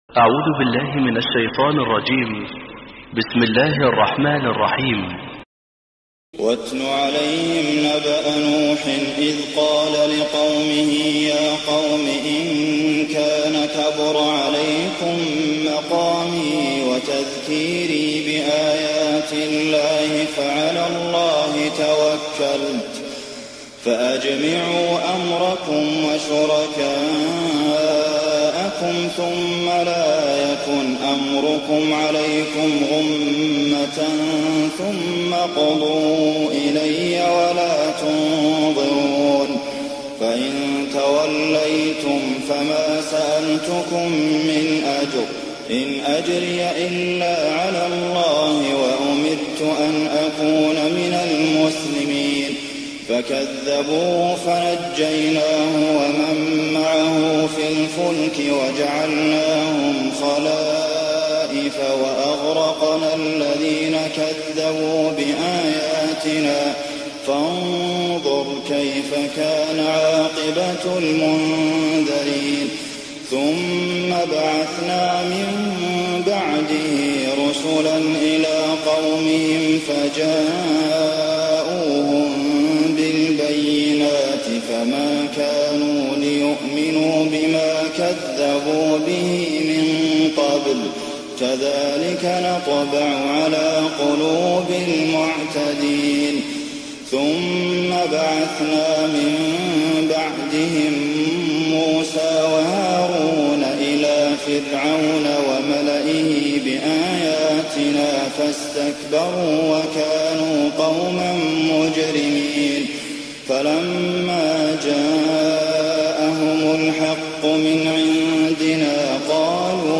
تهجد ليلة 29 رمضان 1419هـ من سورة يونس (71-109) Tahajjud 29th night Ramadan 1419H from Surah Yunus > تراويح الحرم النبوي عام 1419 🕌 > التراويح - تلاوات الحرمين